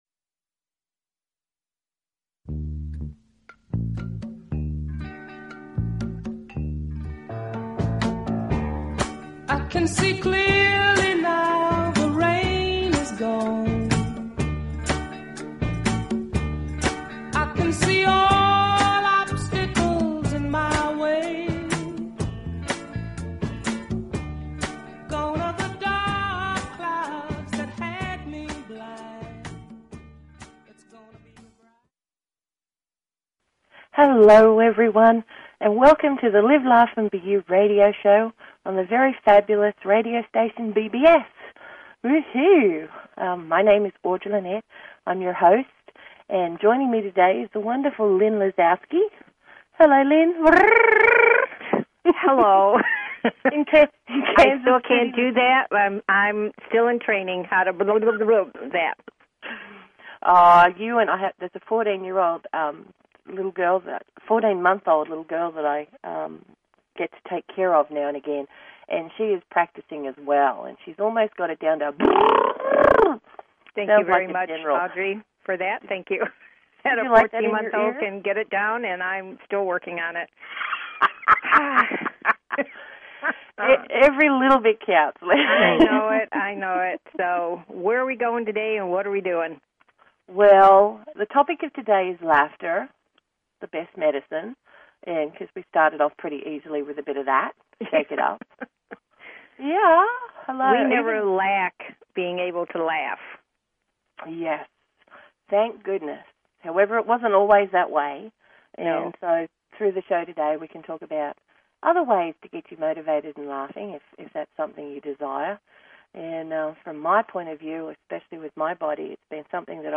Talk Show Episode, Audio Podcast, Live_Laugh_and_BE_You and Courtesy of BBS Radio on , show guests , about , categorized as